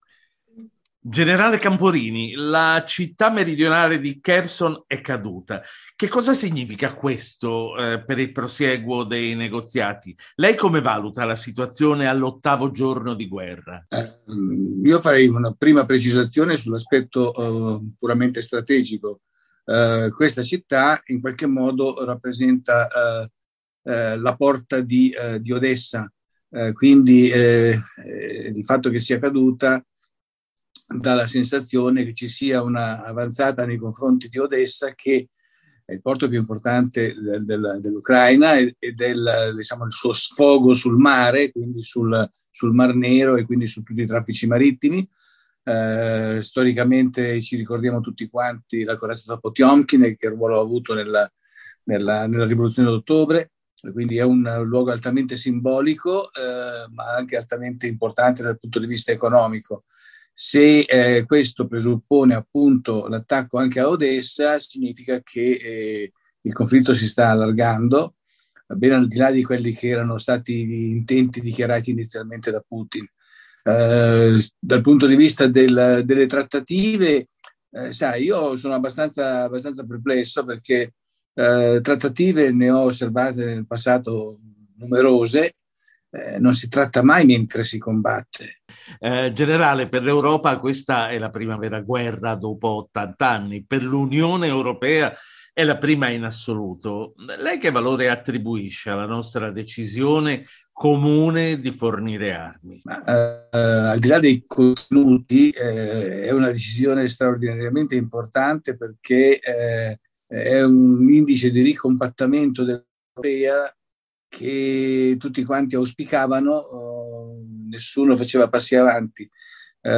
Ecco l’audio-interrvista: